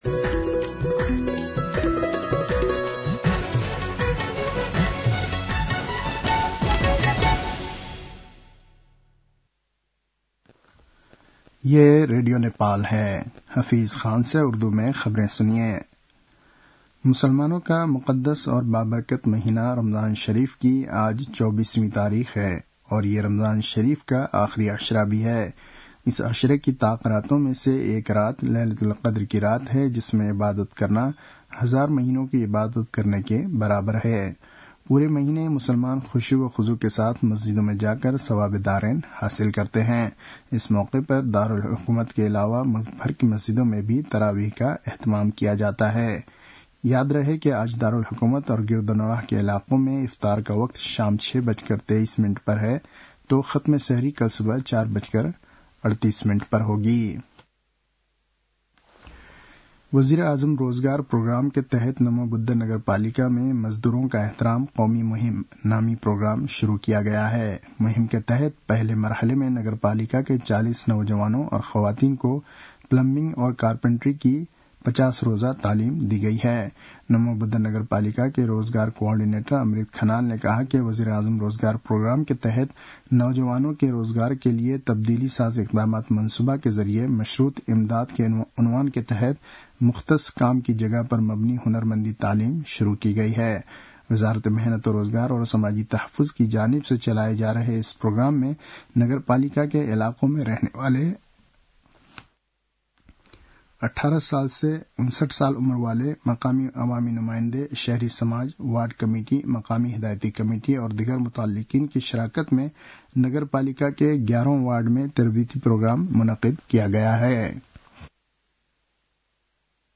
उर्दु भाषामा समाचार : १२ चैत , २०८१